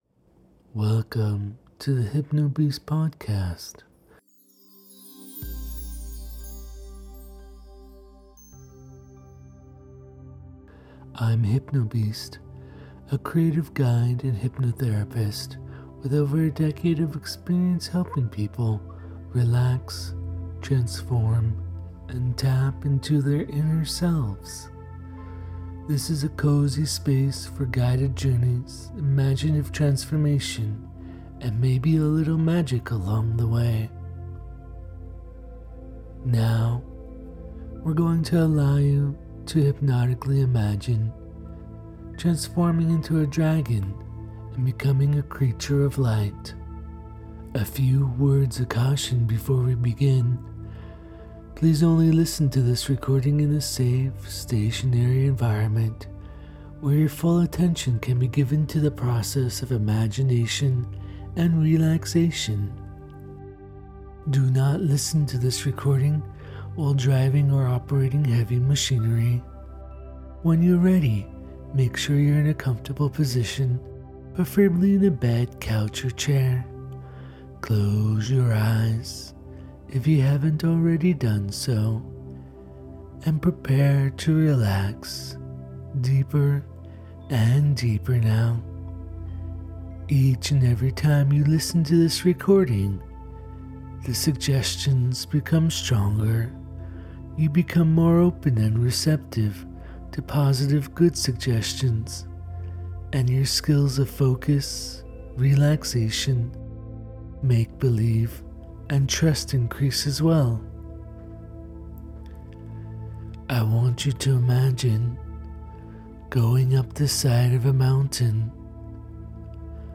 This is a being of warmth, strength, and clarity. Through soothing guidance and gentle imagination, you’ll shed the weight of the world and rise into radiant form.